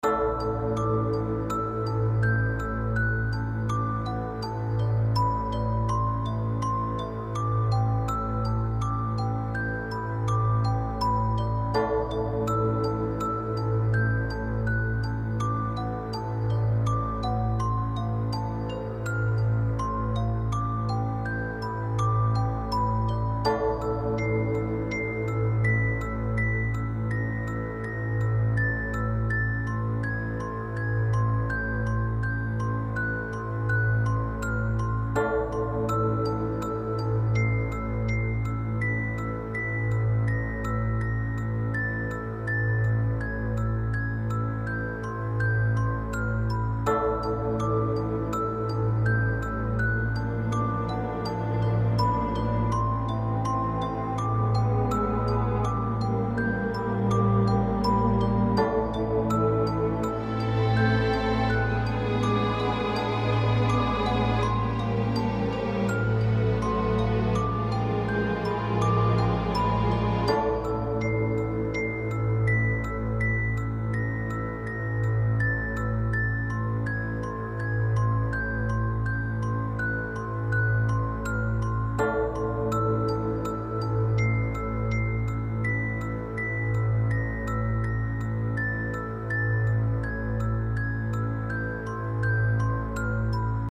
Celesta, strings and bells.
Love the chromatic descent!
The "lead" instrument stands out a little too much.
Classical
Loop